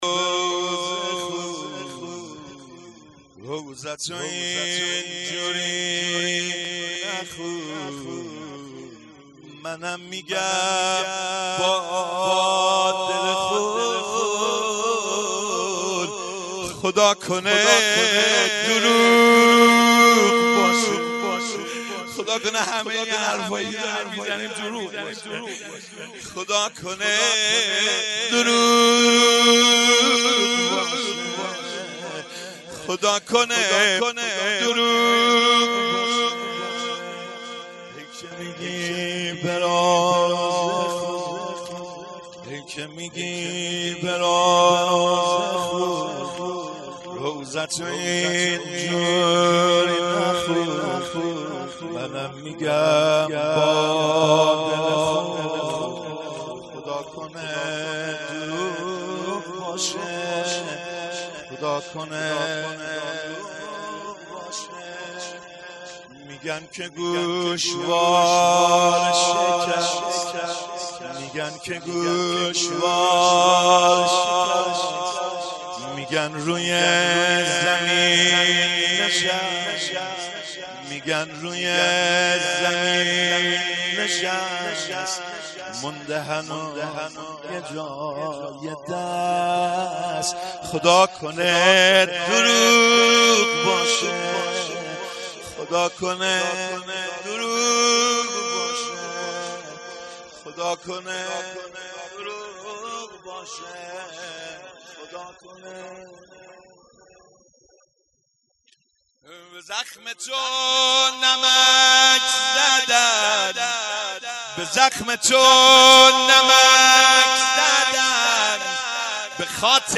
هیئت عقیله بنی هاشم سبزوار
مراسم شهادت حضرت فاطمه زهرا سلام الله علیها آبان ۱۴۰۳